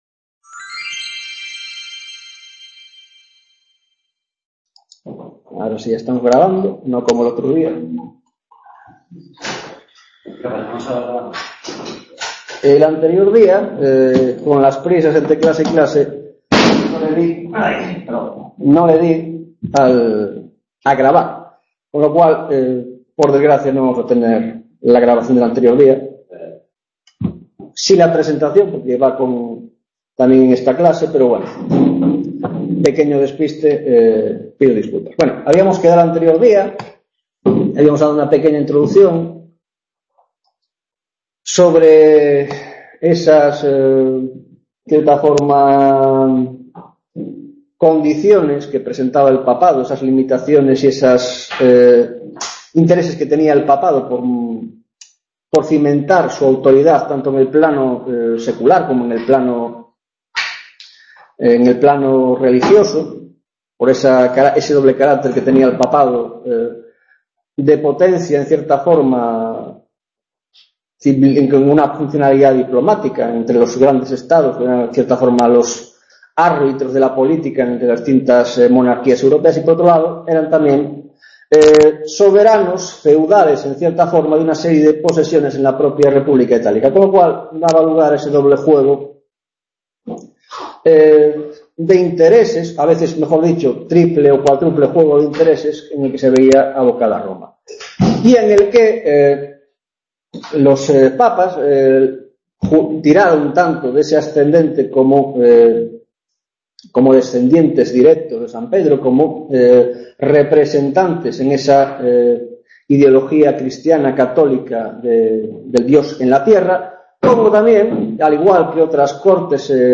6ª Tutoria de la asignatura Arte y Poder en la Edad Moderna - Papado, 2ª parte La primera parte de este tema (la Introducción) por un error en la grabación no quedo grabada, disculpad las molestias